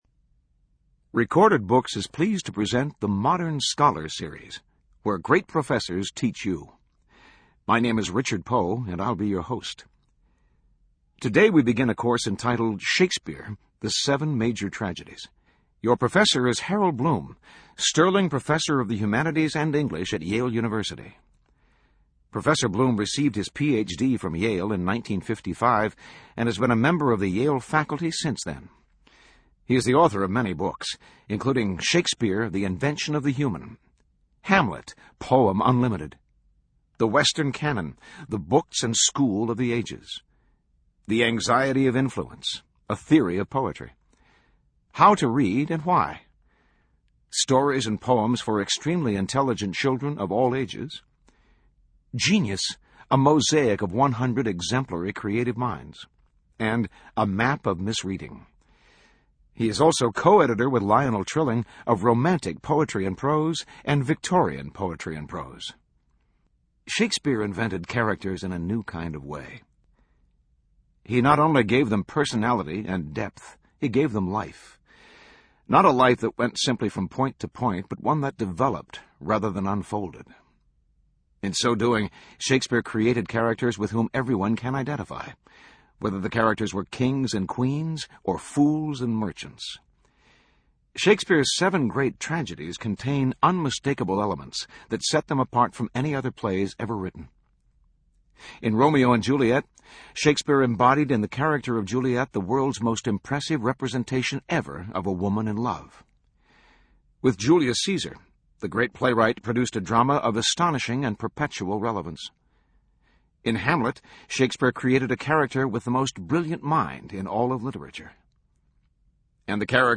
In this audio lecture provided as part of the Modern Scholar Series, noted author and literary critic Harold Bloom provides his unparalleled analysis of Shakespeare's tragic love story.